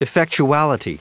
Prononciation du mot effectuality en anglais (fichier audio)
Prononciation du mot : effectuality